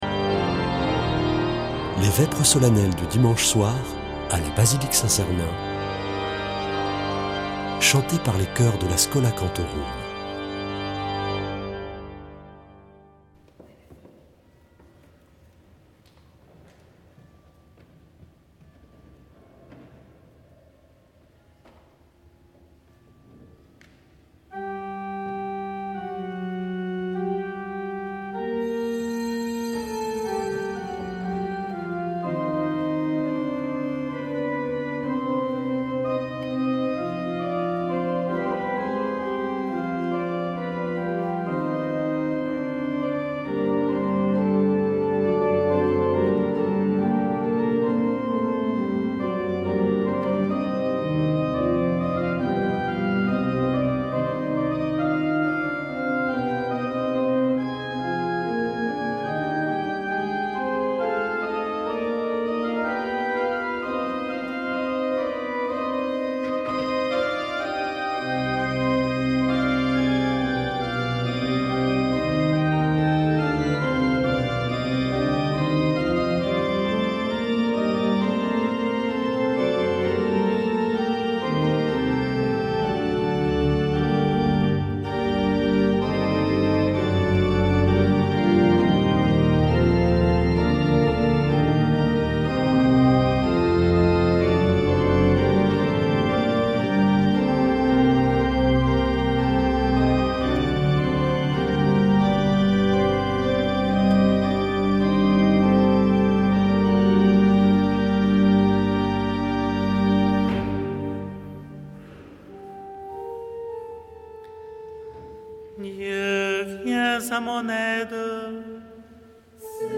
Vêpres de Saint Sernin du 30 juin
Une émission présentée par Schola Saint Sernin Chanteurs